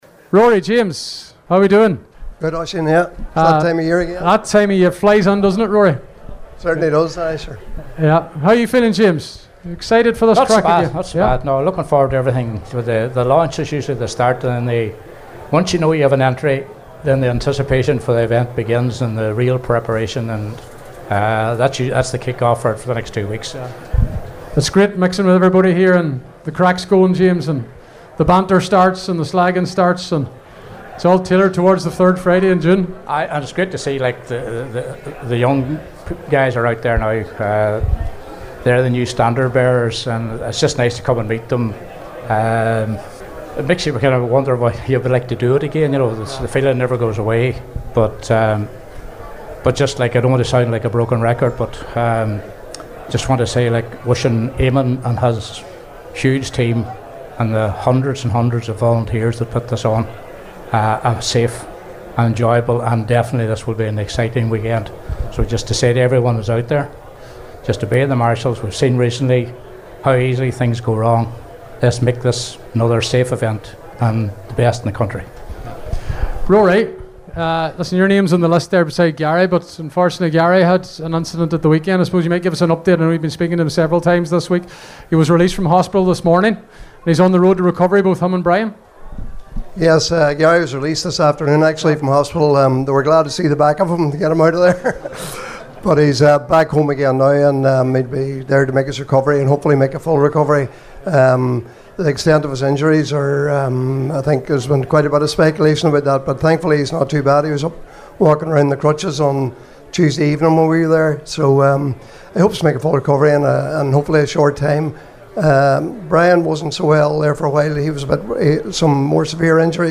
Donegal International Rally Launch night interviews